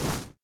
default_grass_footstep.3.ogg